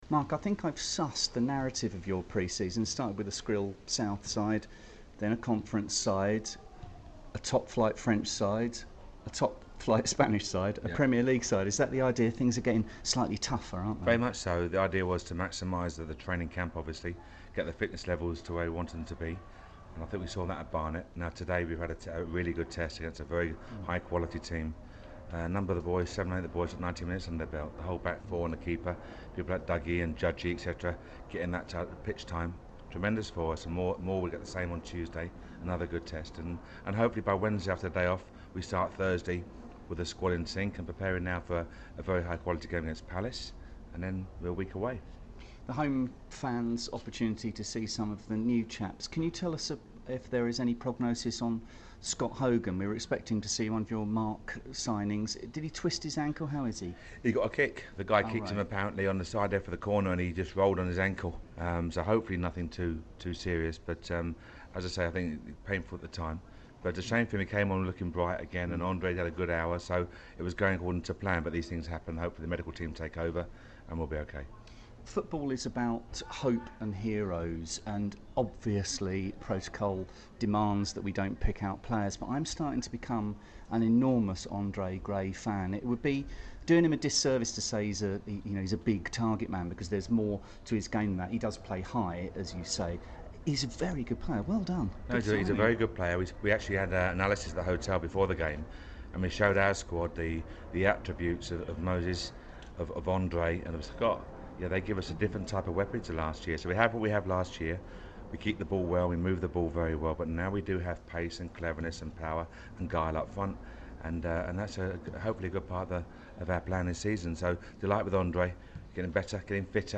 Brentford manager Mark Warburton's post-match interview with BBC London